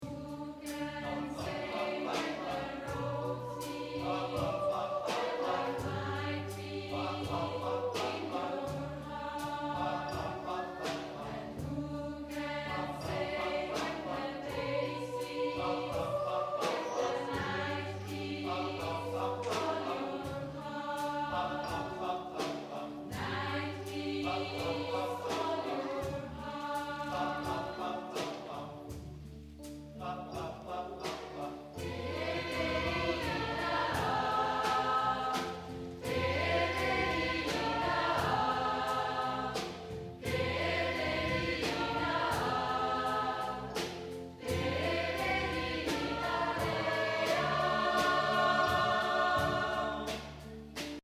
Live-Aufnahmen Konzert Schneisingen 2007
Live-Konzert vom 20./21. Januar 2007 in der kath. Kirche Schneisingen.
Only Time Chor